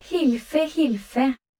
ggl_nb-NO-Wavenet-C_*075.wav